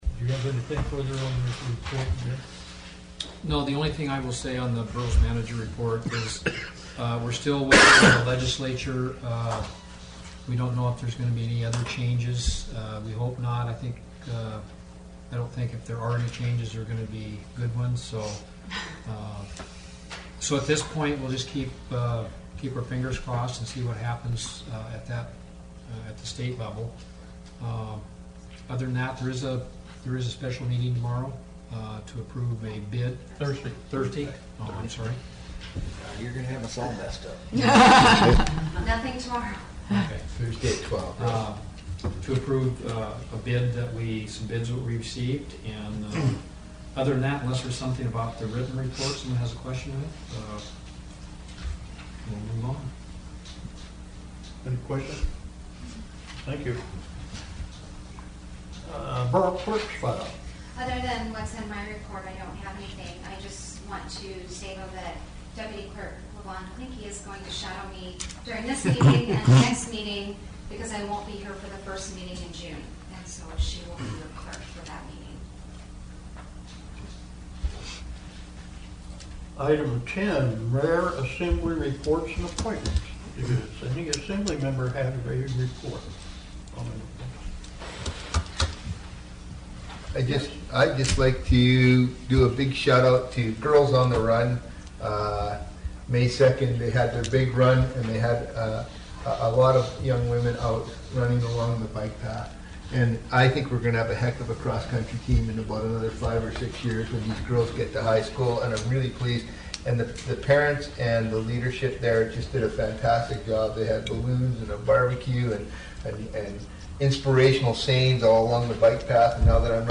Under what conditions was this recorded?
Wrangell's Borough Assembly held its regular meeting Tuesday, May 12 in the Assembly Chambers. City and Borough of Wrangell Borough Assembly Meeting AGENDA May 12, 2015 – 7:00 p.m. Location: Assembly Chambers, City Hall